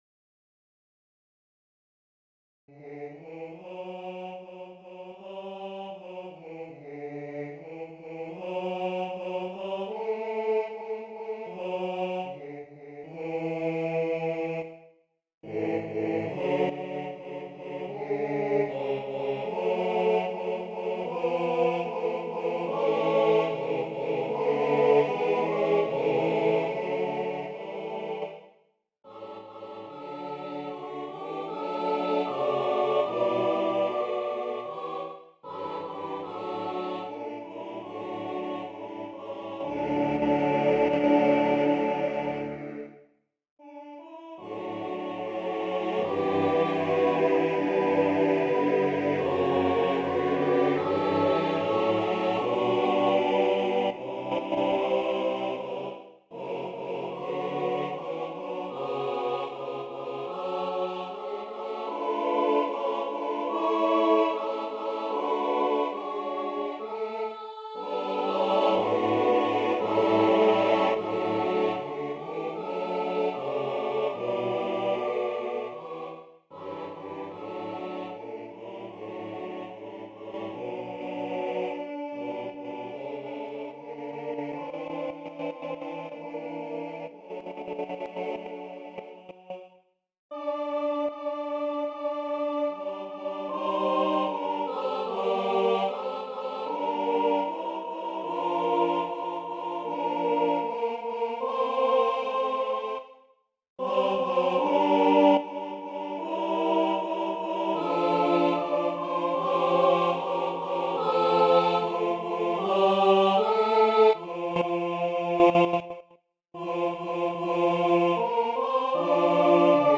Для смешанного хора (a cappella)